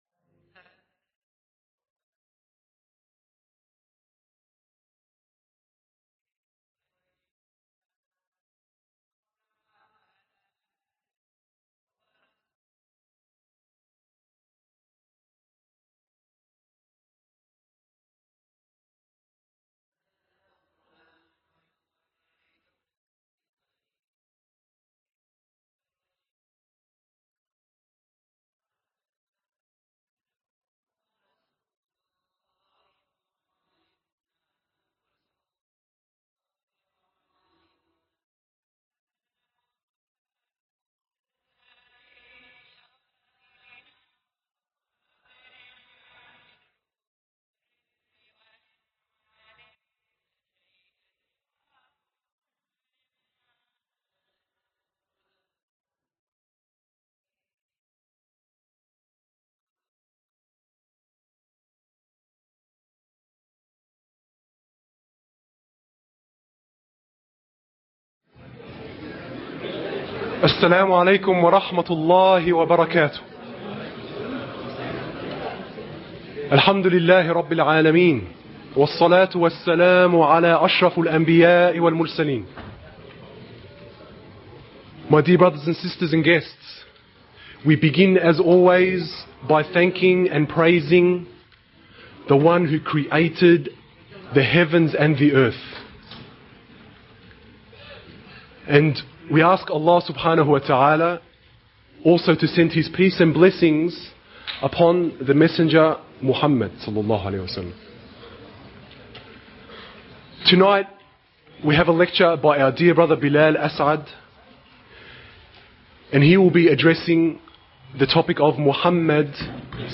Trailor/Preview of Lecture